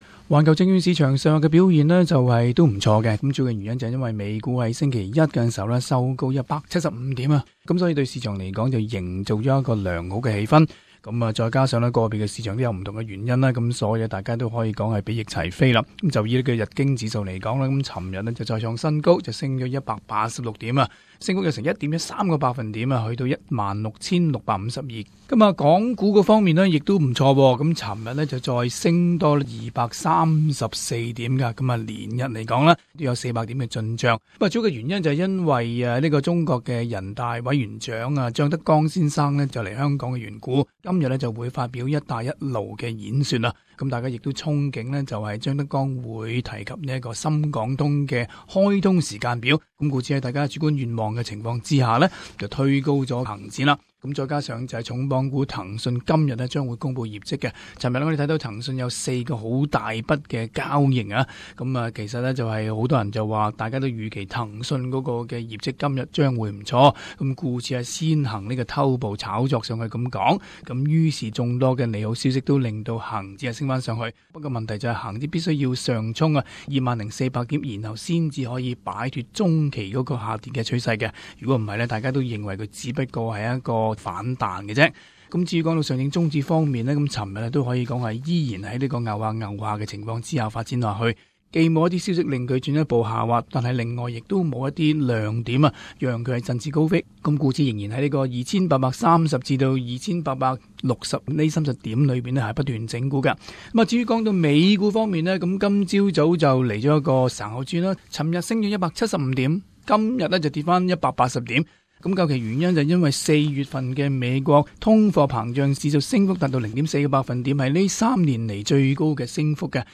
Global Finance News: Interview Morgan's Analyst